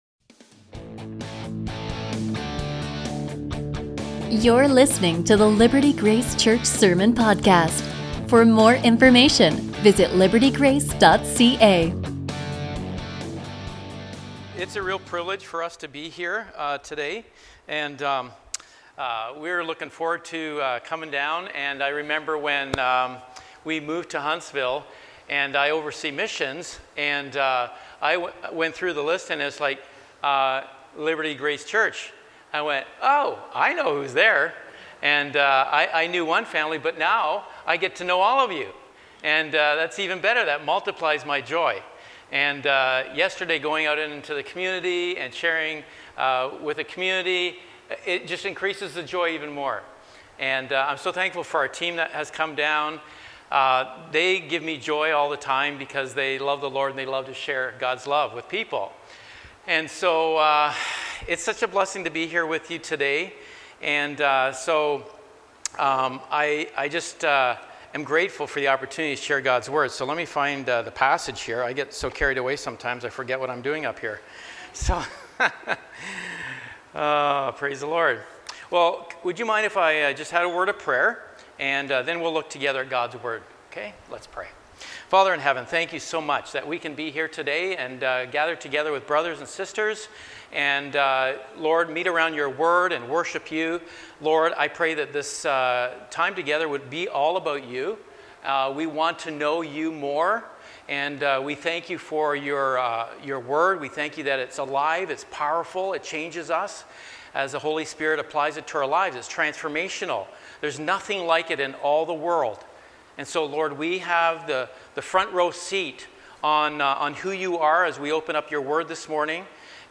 A sermon from 2 Chronicles 7:14